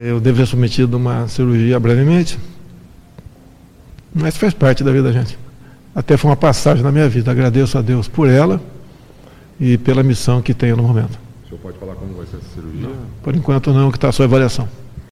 Bolsonaro fala sobre nova cirurgia para correção de hérnia